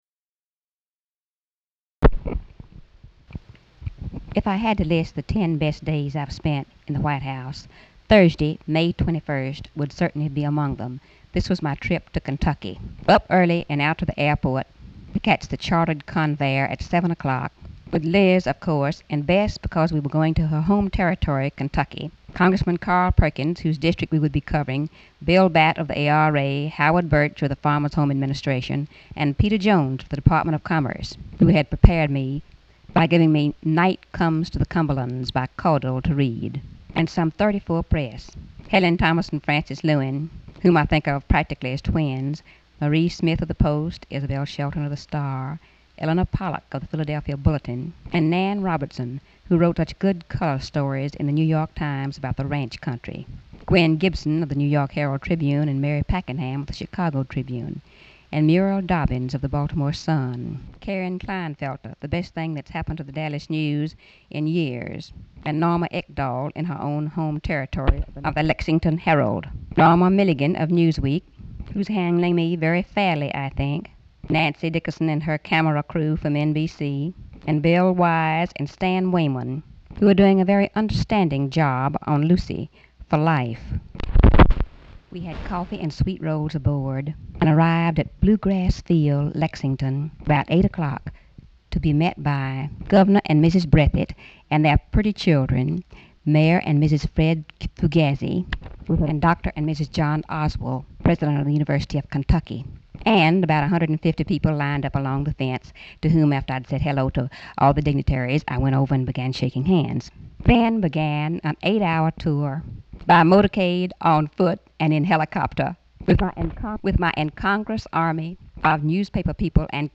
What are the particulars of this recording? Location Detail White House, Washington, DC